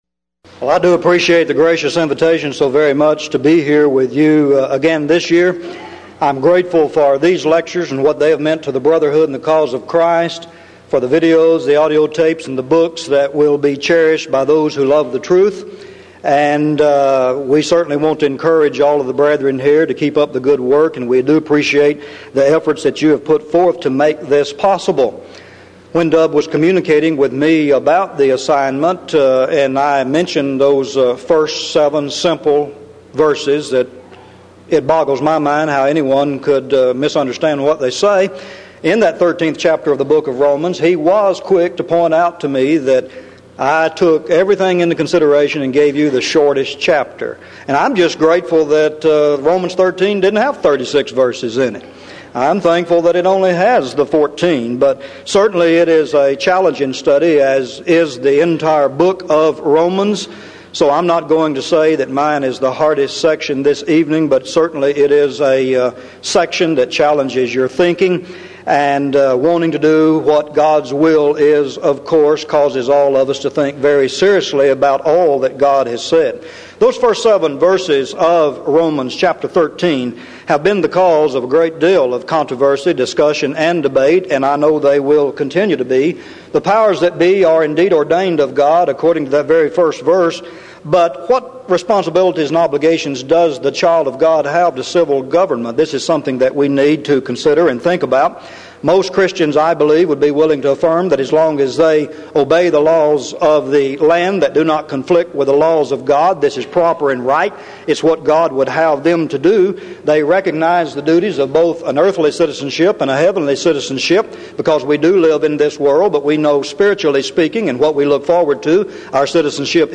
Event: 1996 Denton Lectures Theme/Title: Studies In The Book Of Romans
this lecture